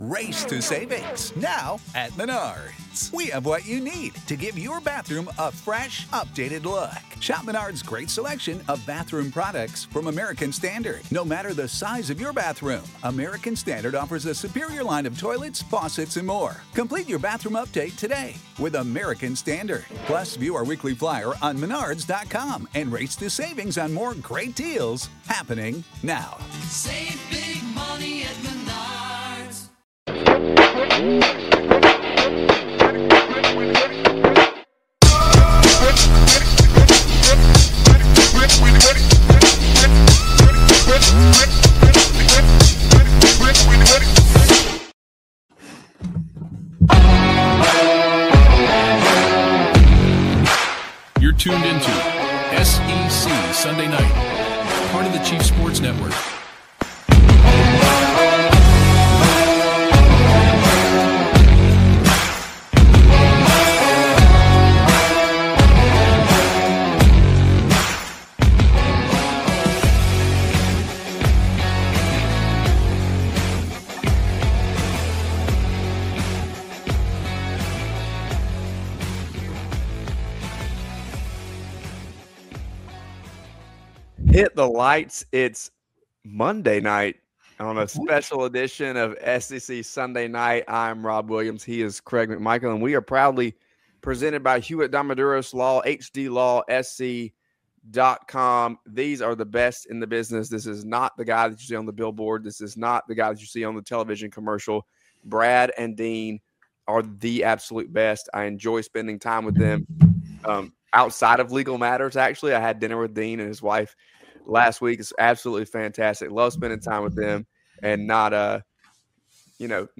joins the show for an in-depth discussion on the state of the sport as only he can.